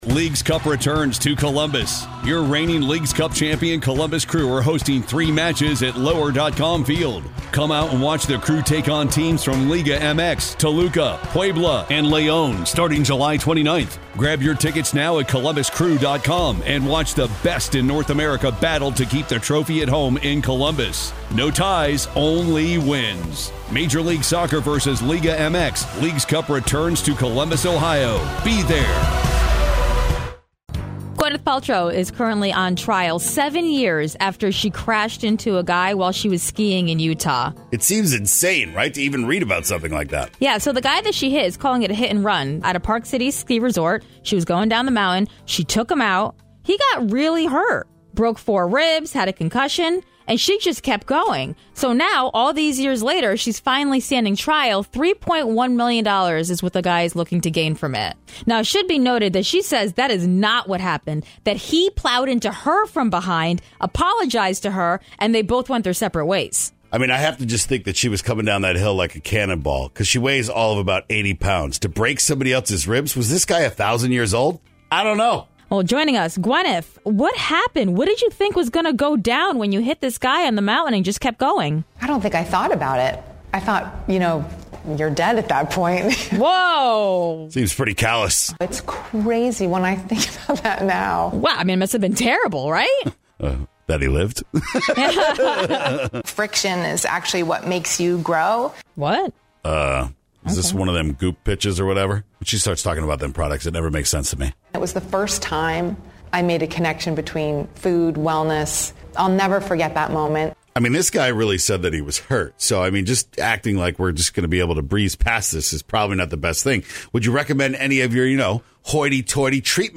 Gwyneth Interview